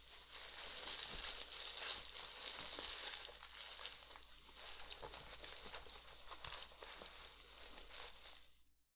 カバンの中を漁る音
カバンガサゴソ.wav